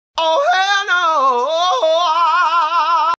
Play, download and share ho heel nah original sound button!!!!
oh-hell-nah-sound-effect.mp3